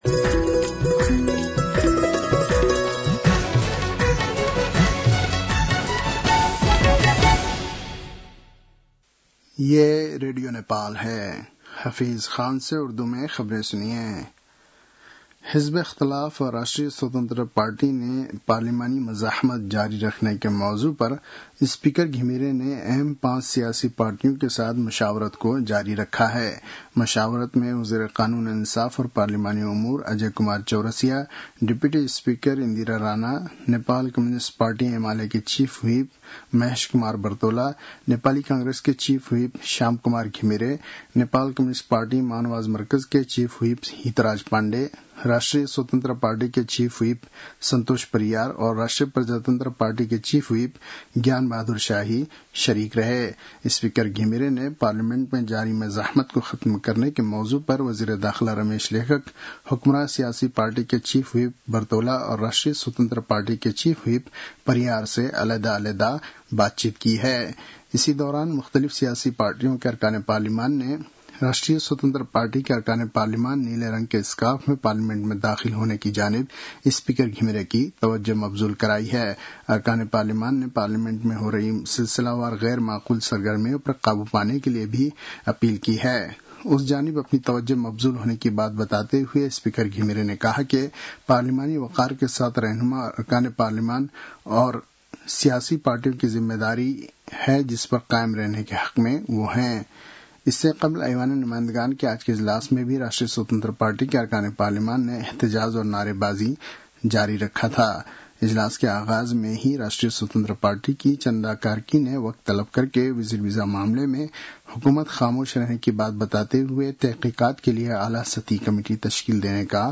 उर्दु भाषामा समाचार : ३ असार , २०८२